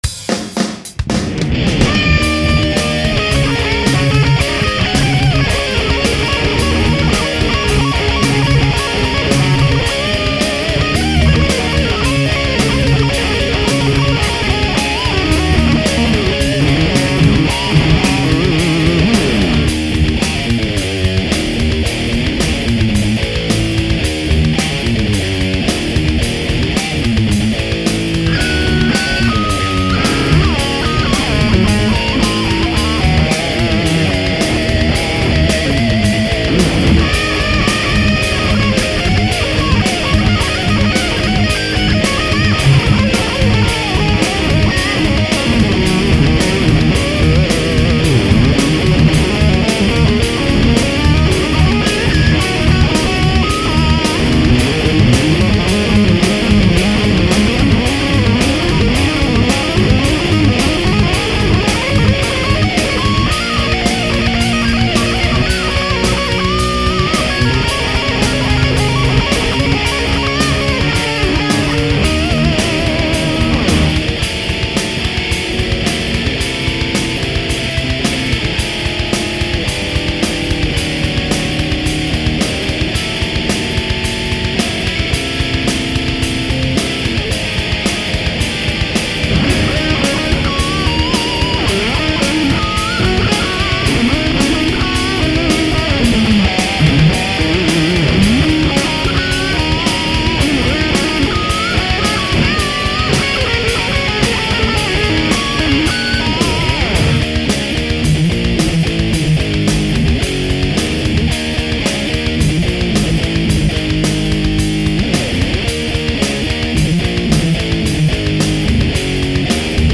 red zone 　これはまさに「レッドゾーン!!」 　聴き返してみるとすごい勢いだもん。